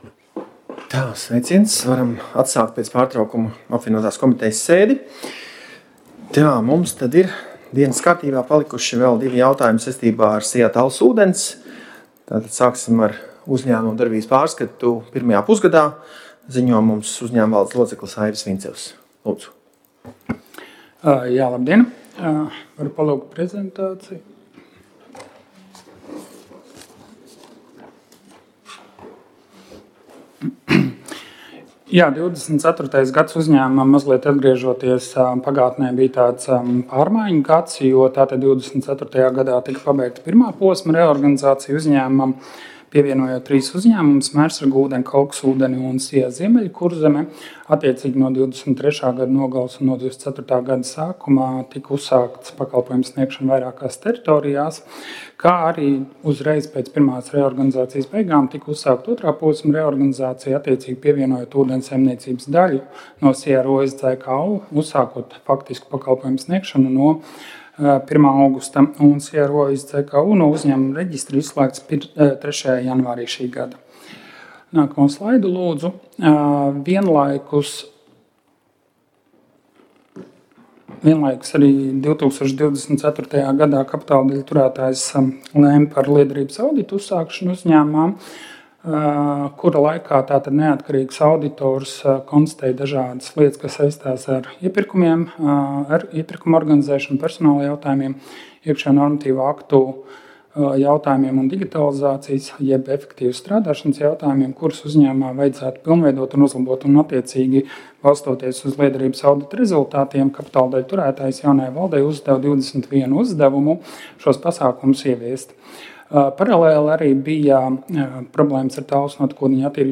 Komitejas sēdes audio